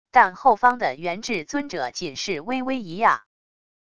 但后方的元志尊者仅是微微一讶wav音频